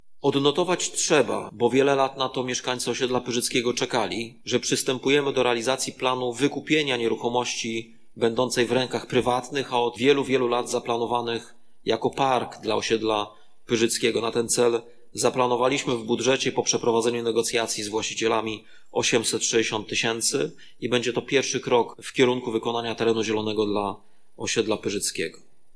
Na sesji rady miejskiej w Stargardzie Prezydent miasta Rafał Zając przeczytał w obecności radnych uchwałę dotyczącą budżetu miasta na 2022 rok.
Mówi Prezydent Stargardu Rafał Zając.